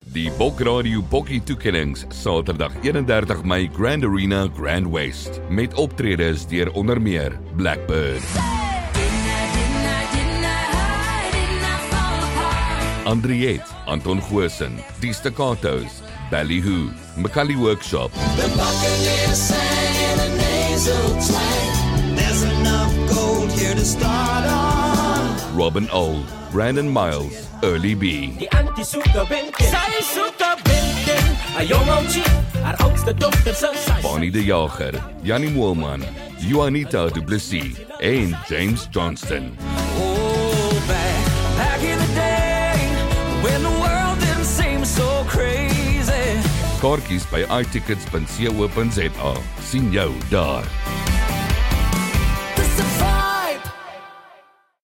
Professional voiceover work for commercials, promos, podcasts, and more.
Bokkies_2025_Voiceover_-_Afrikaans.mp3